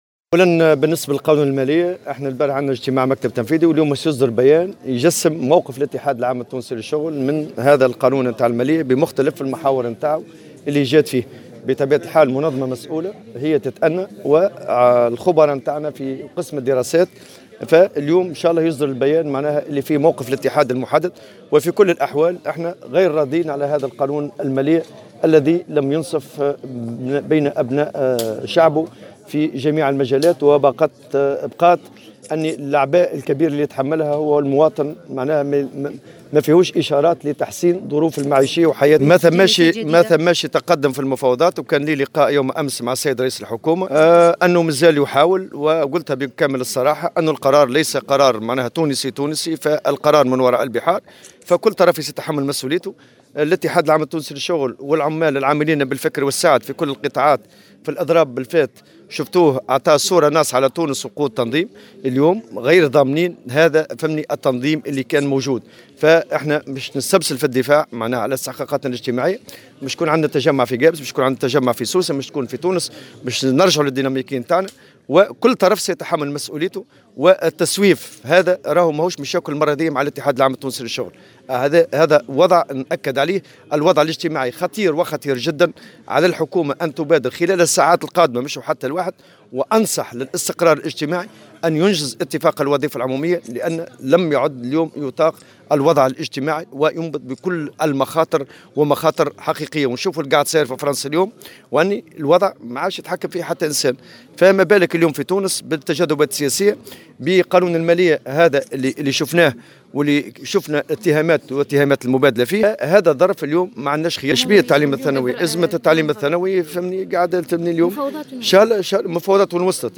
وأضاف الطبوبي في تصريح لمراسلة الجوهرة "اف ام" أن الإتحاد غير راض عموما عن قانون المالية الذي لم ينصف أبناء شعبه في جميع المجالات مؤكدا أن الأعباء الكبيرة يتحملها المواطن وليس فيه أية اشارات على تحسين ظروف المعيشة.